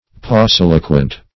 Pauciloquent \Pau*cil"o*quent\
pauciloquent.mp3